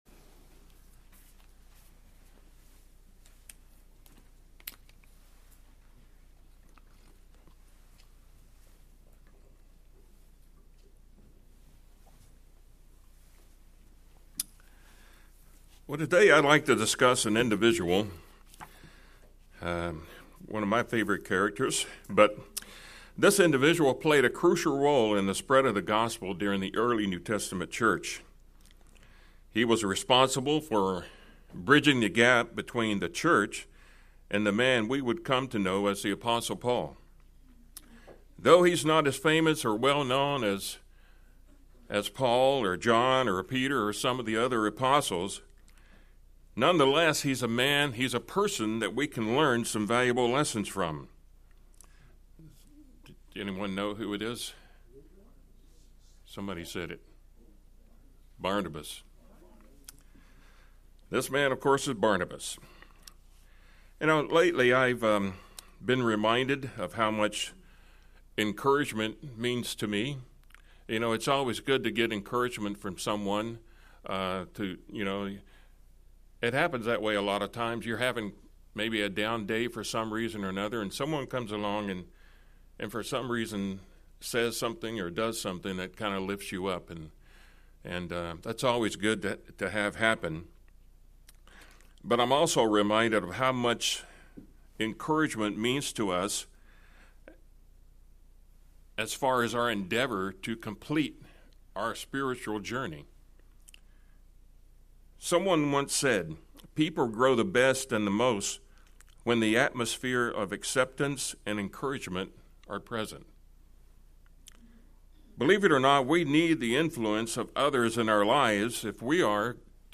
Barnabas was given the name by the apostles son of encouragement. In today's sermon we will learn the lessons that we can apply to our lives from Barnabas as we strive to encourage to one another.